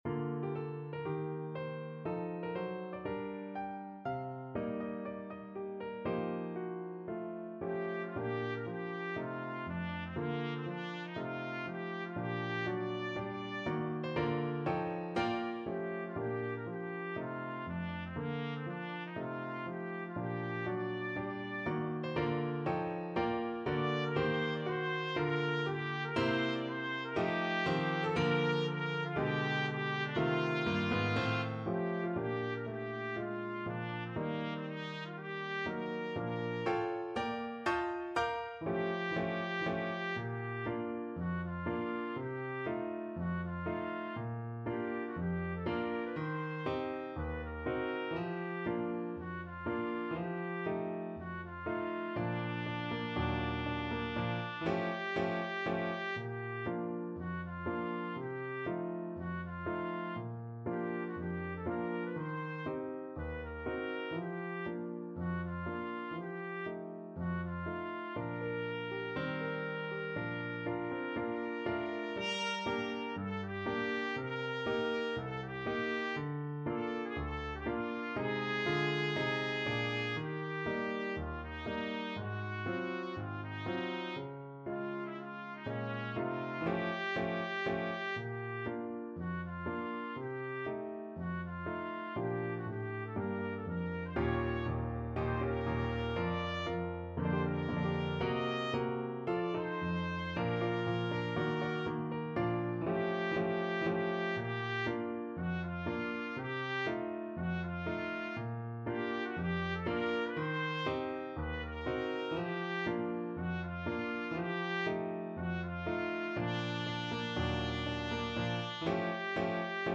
4/4 (View more 4/4 Music)
~ = 120 Moderato